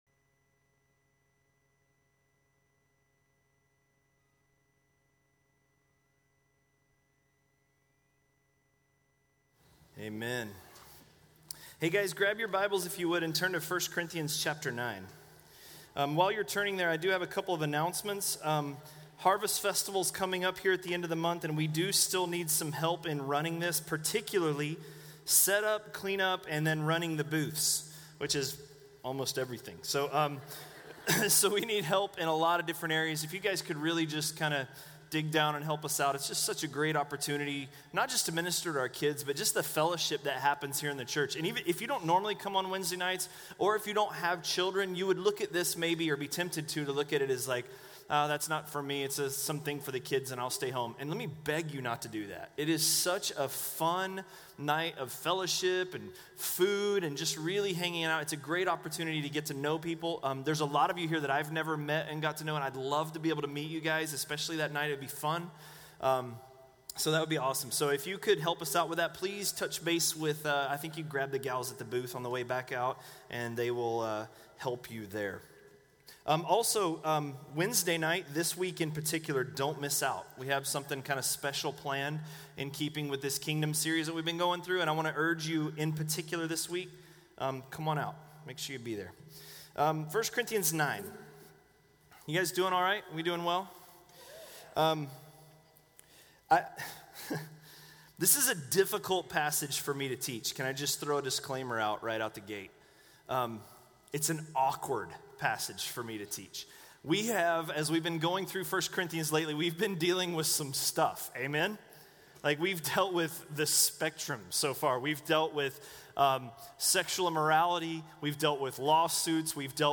A message from the series "1 Corinthians." 1 Corinthians 9:1–9:18